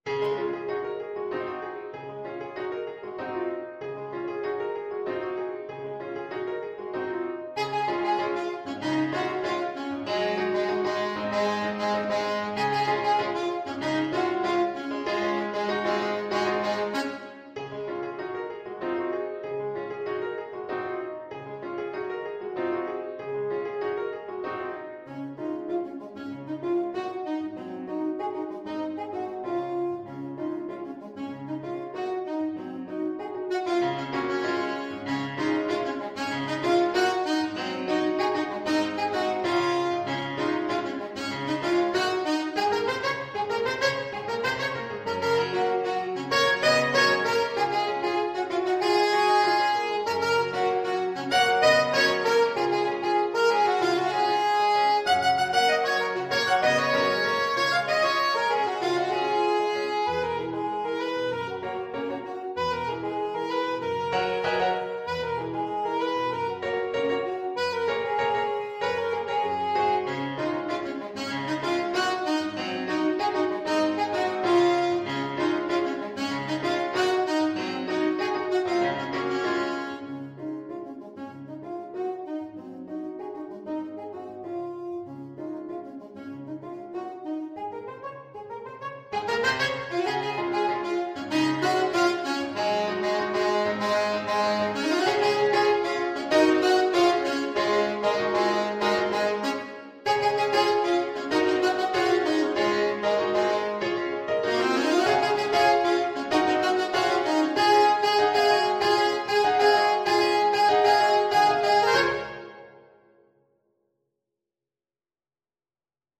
Alto Saxophone version
Alto Saxophone
4/4 (View more 4/4 Music)
Classical (View more Classical Saxophone Music)